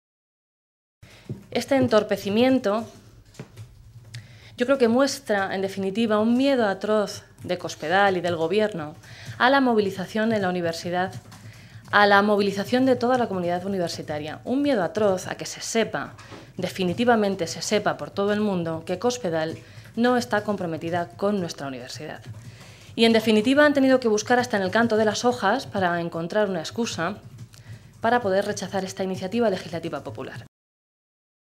Blanca Fernández, secretaria segunda de la Mesa de las Cortes y diputada regional, en rueda de prensa
Cortes de audio de la rueda de prensa